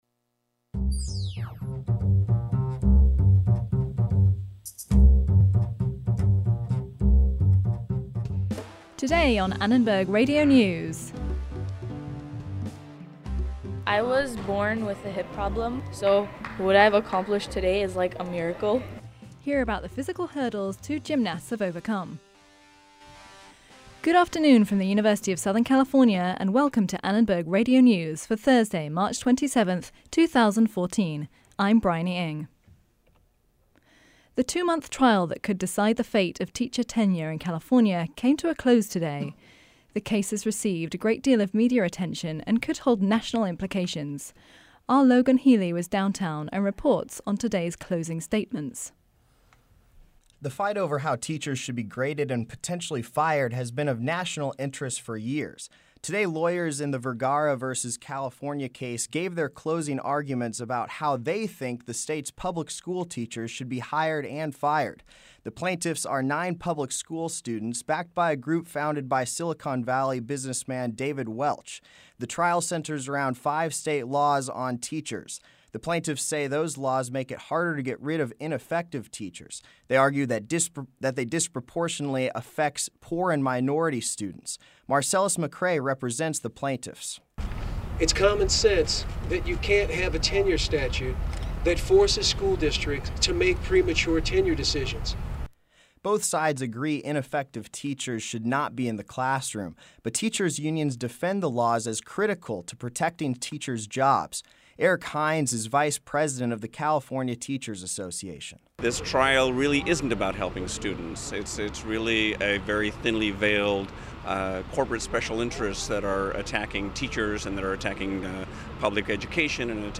About 25 percent of Californians live in poverty, our host spoke with two social policy makers about possible solutions.